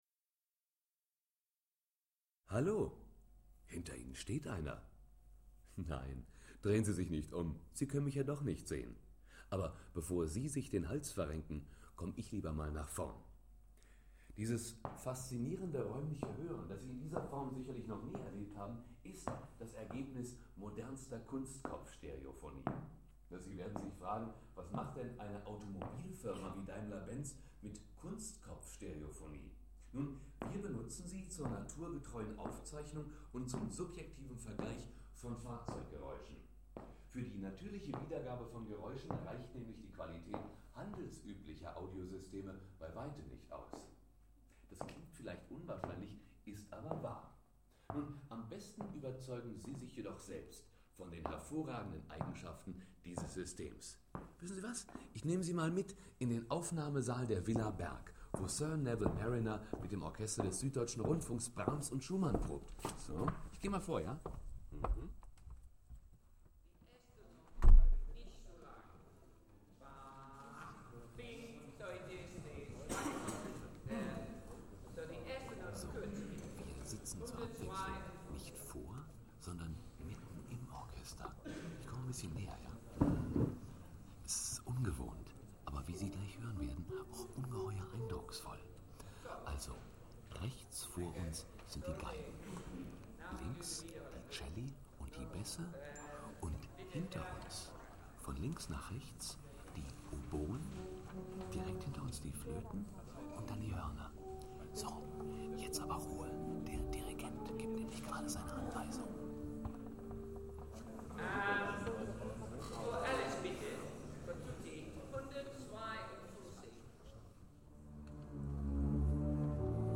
Kunstkopfstereophonie Mercedes-Benz Welt Mobil